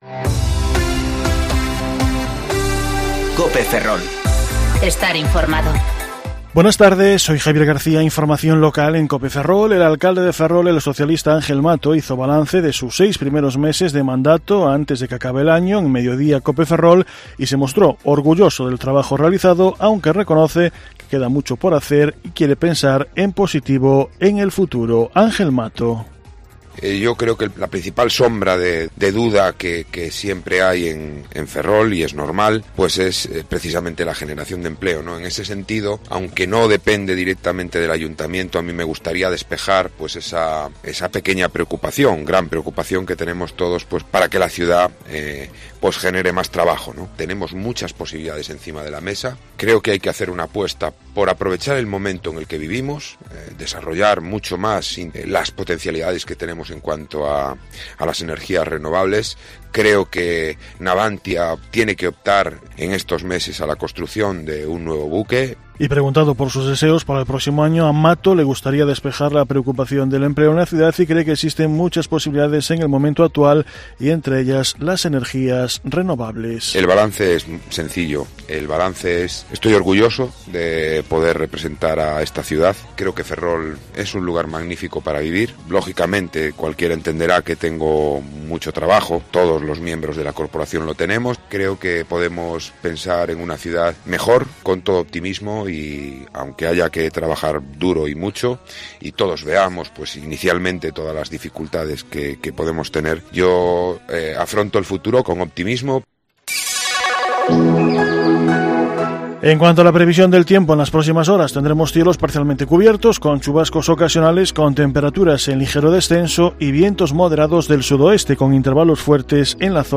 Informativo Mediodía Cope Ferrol 20/12/2019 (De 14.20 a 14.30 horas)